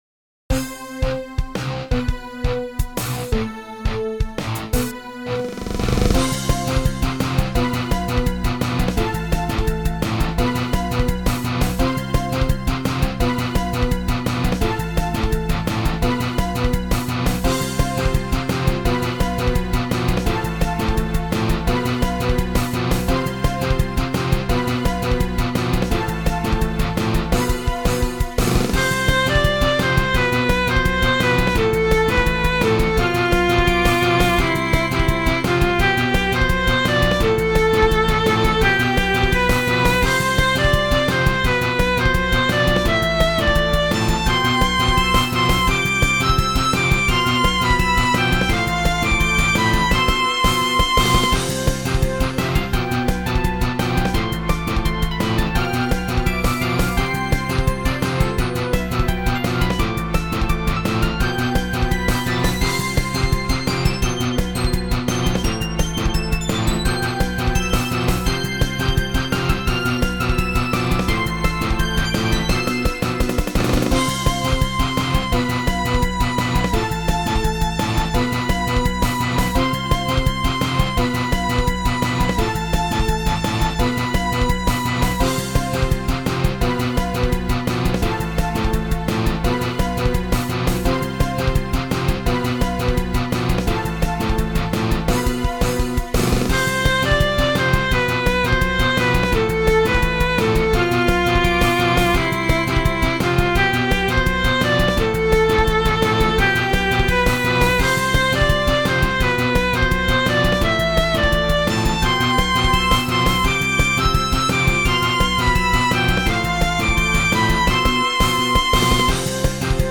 ジャンル キラキラPop
説明 割と高音がメインの曲です。